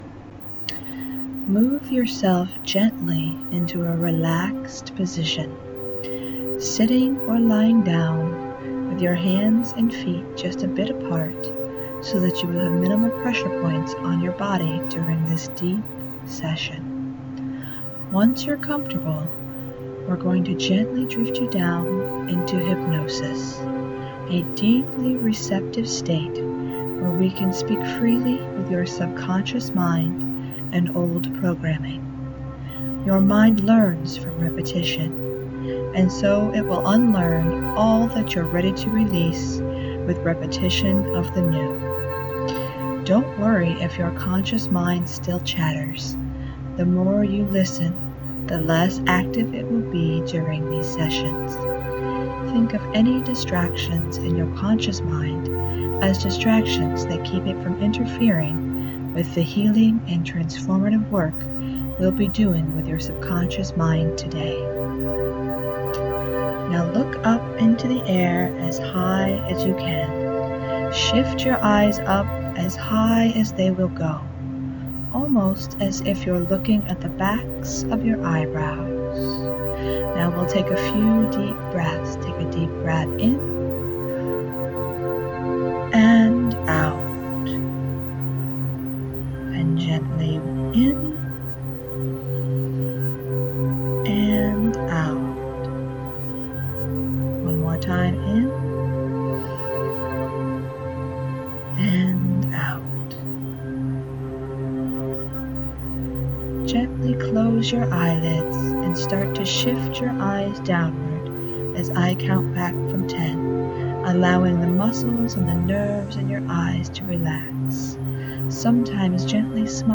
Here you will find my first two self-hypnosis audio files.
FastingforFatLoss_Sample1_wSubliminals.mp3